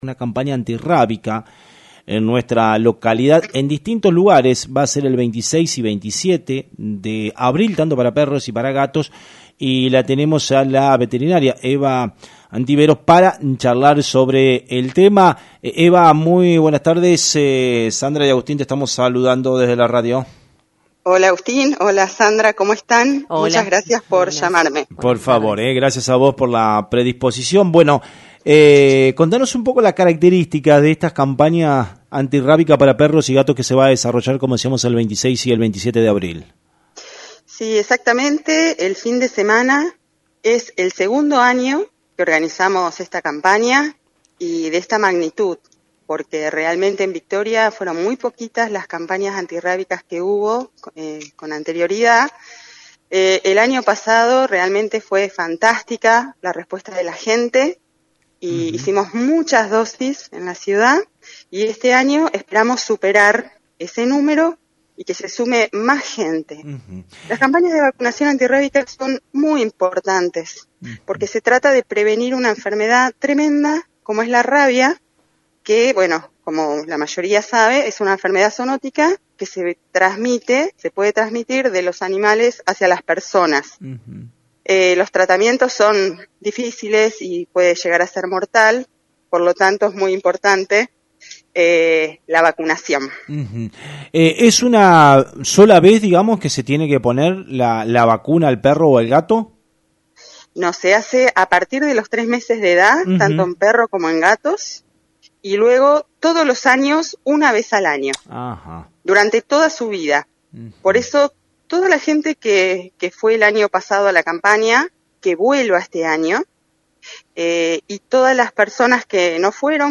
En una entrevista en el programa Radionoticias de FM 90.3